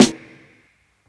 dsaf-snr (2).wav